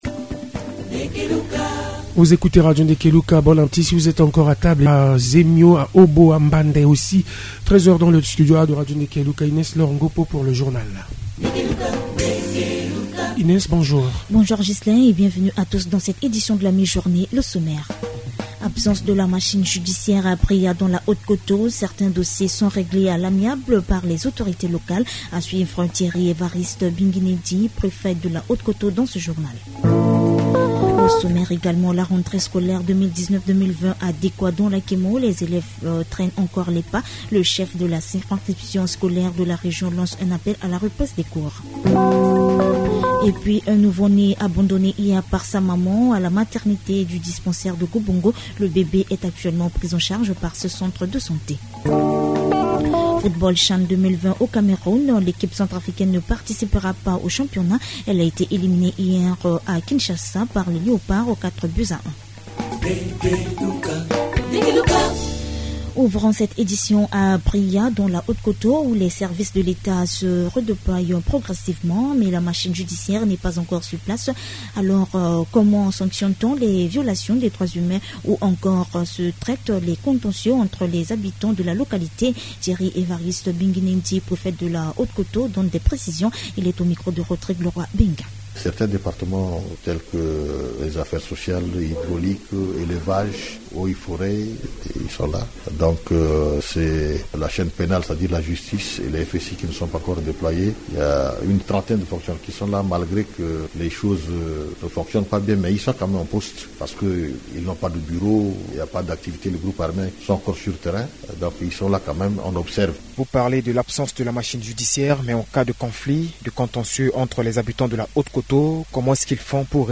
Journal Français